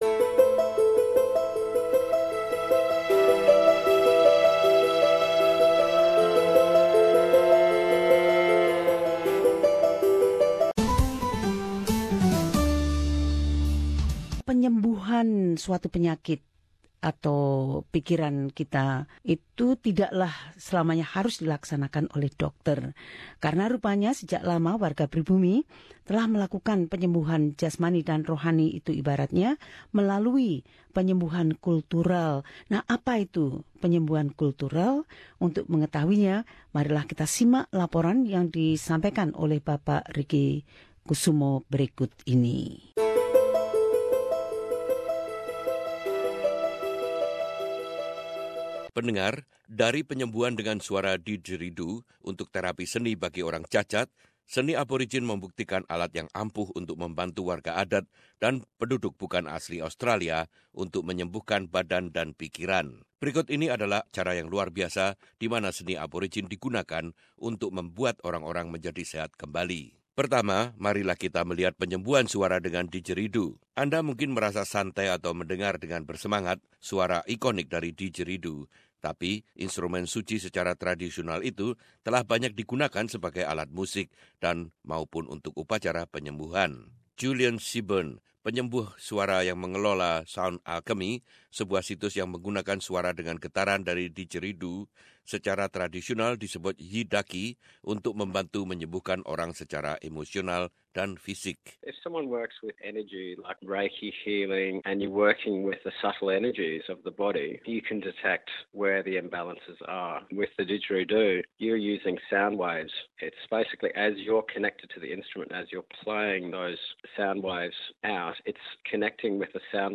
Laporan ini menjelaskan kekuatan penyembuhan seni Pribumi.